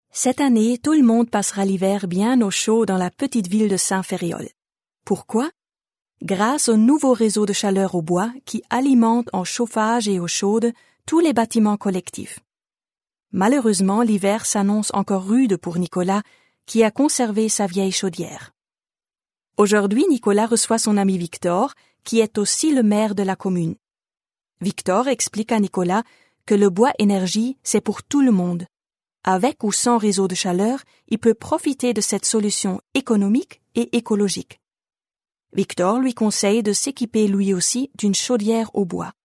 Showreel Emotionen - Mädchen 12-14 (mutig, ängstlich, freudig)